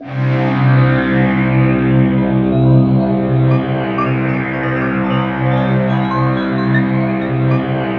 SYN JD80004L.wav